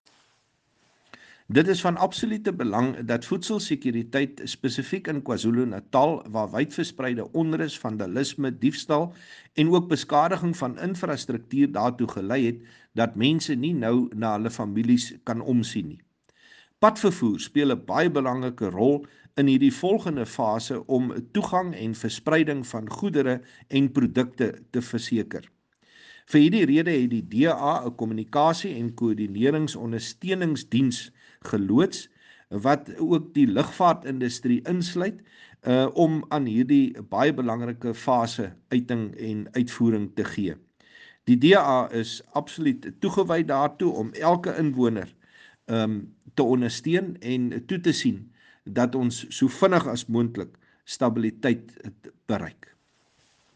Issued by Chris Hunsinger MP – DA Shadow Minister of Transport
Afrikaans soundbites by Chris Hunsinger MP.